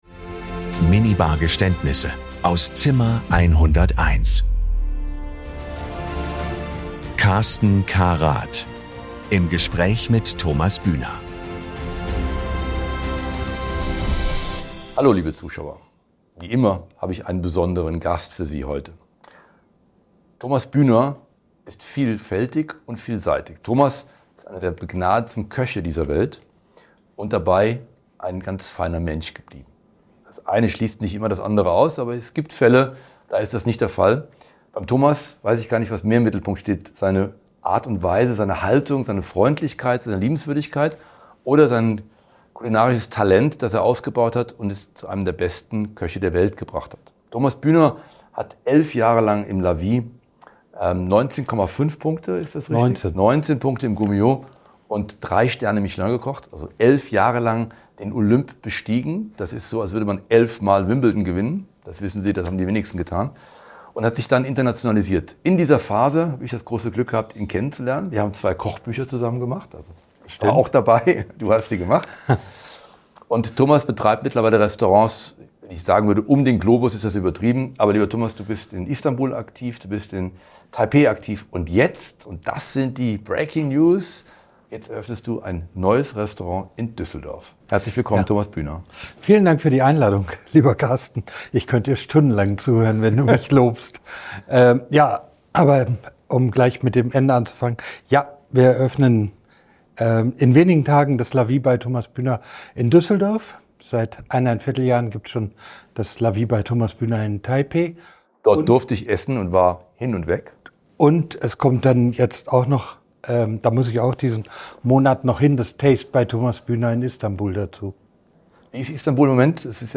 Es wird gelacht, reflektiert und manchmal auch gestanden.
Ein Gespräch über Kulinarische Exzellenz, Konsequenz und den Mut, sich immer wieder neu zu erfinden.